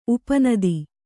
♪ upa nadi